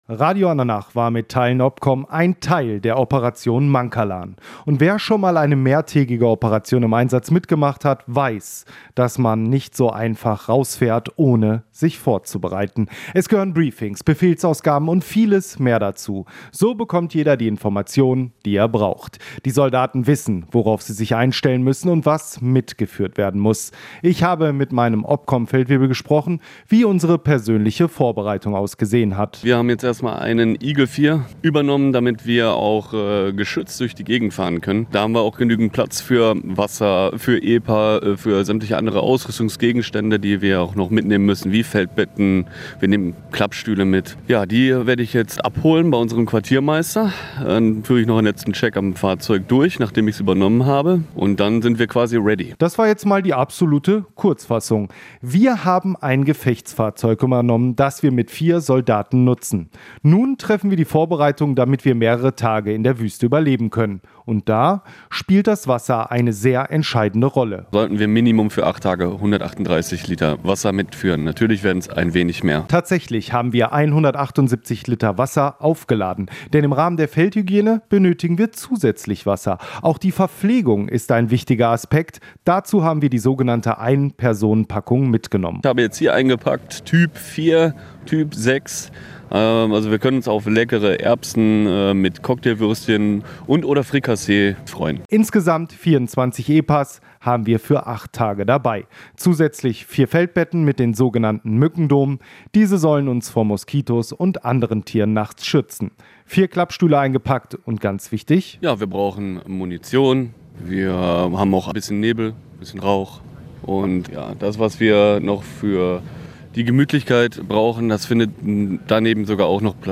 "Marschbereitschaft herstellen" ein kurzer Befehl der nicht wenig beinhaltet, denn eine gute Vorbereitung (bevor man das Camp verlässt) ist das A und O. Was alles zur Vorbereitung gehört, hören Sie in diesem Audiobeitrag.